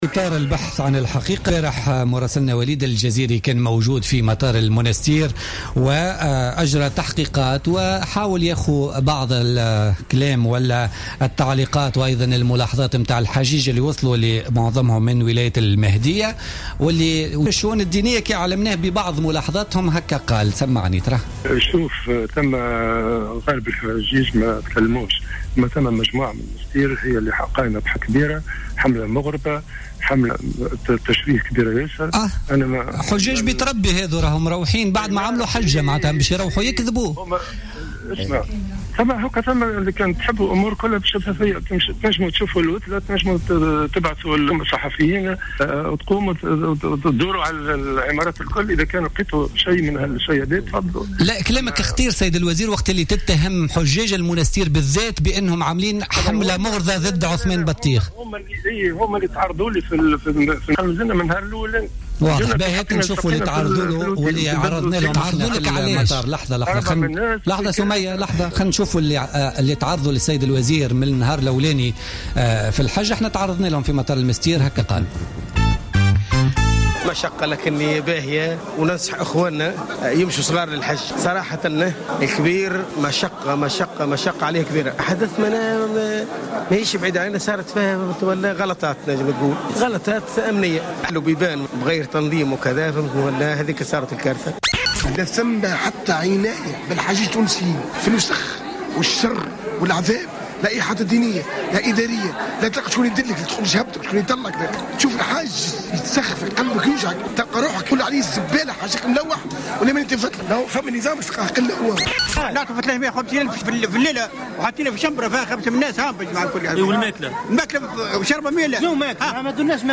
الحجيج يتحدثون تحميل المشاركة علي مقالات أخرى ثقافة 19/07/2025 افتتاح المقرّ الجديد لمركز علوم وتقنيات التراث بالحلفاوين وطنية 19/07/2025 اليوم..